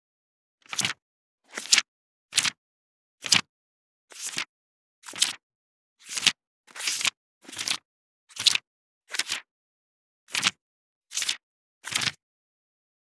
1.本ページめくり【無料効果音】
ASMR効果音本をめくる
ASMR